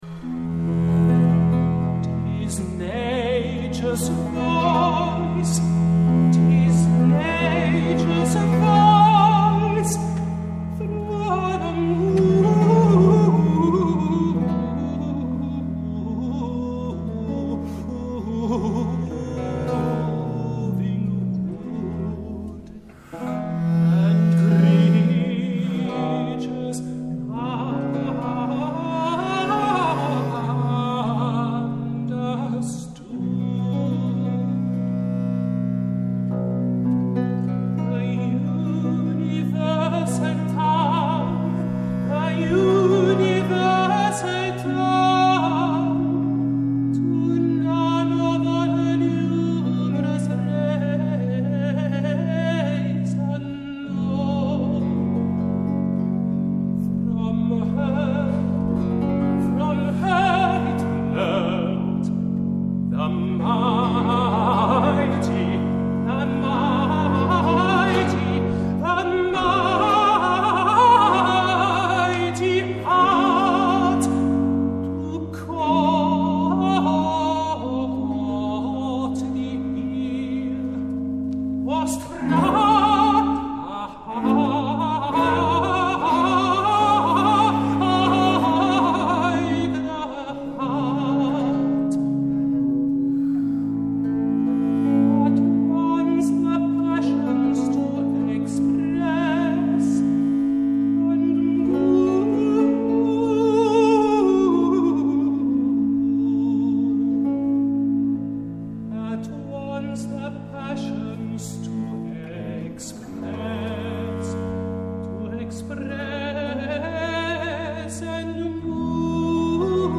Countertenor/Altus
Alte Musik | Konzert
Theorbe
Gambe